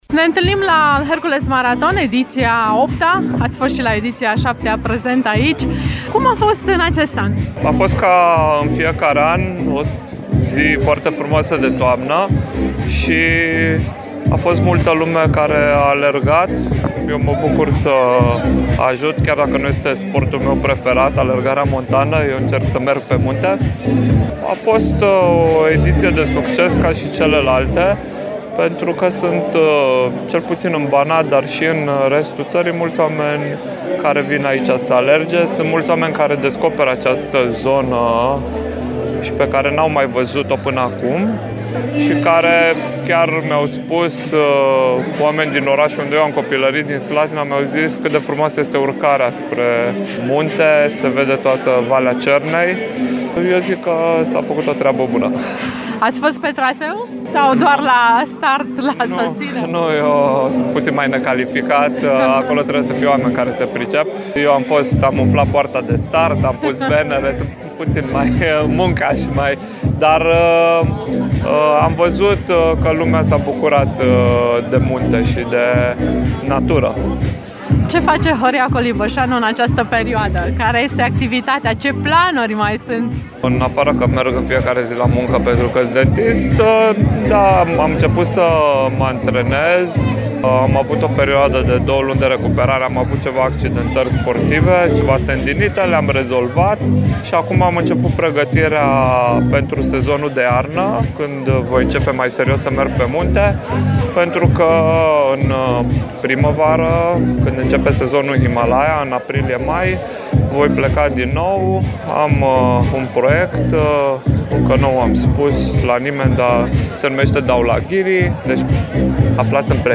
Startul în Hercules Maraton a fost dat și în acest an de cunoscutul alpinist timișorean Horia Colibășanu, care ne-a povestit, în exclusivitate, despre noua sa aventură în Himalaya: